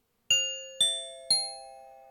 Hovering_finch.ogg